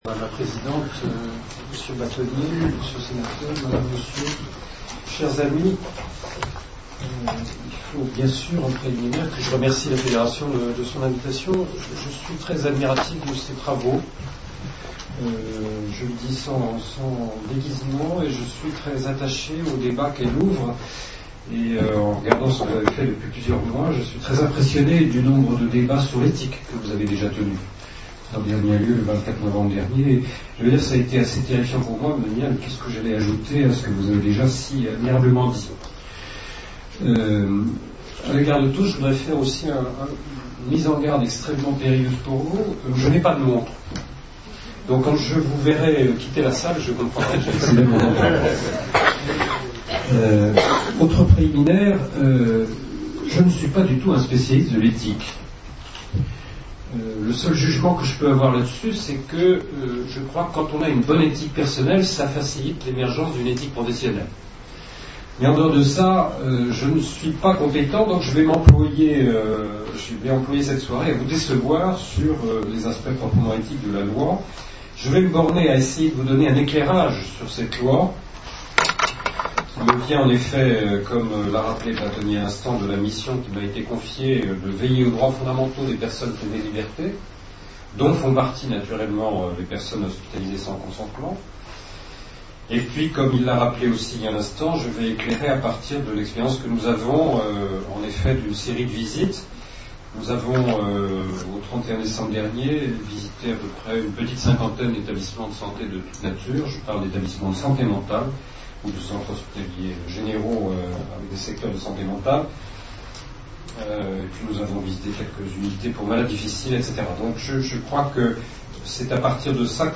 Conférencier